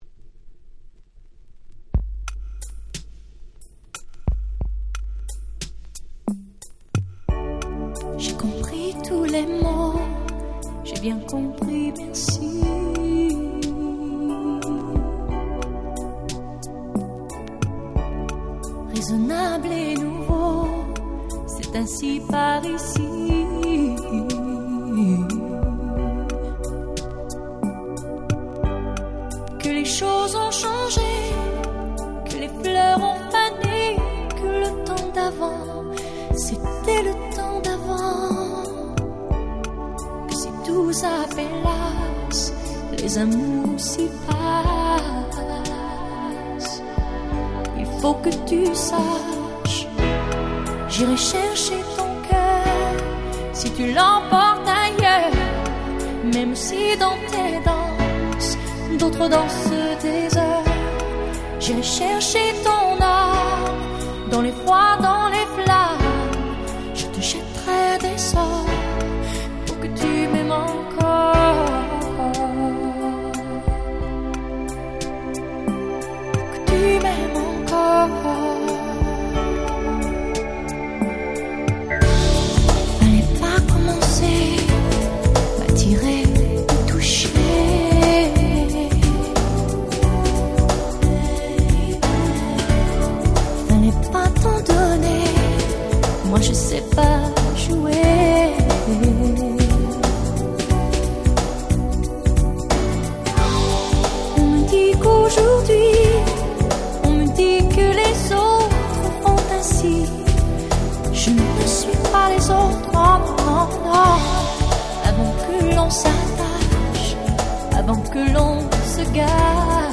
98' Very Nice R&B / Ground Beat !!
アンニュイな雰囲気の極上Ground Beatで非常に人気の1枚です！！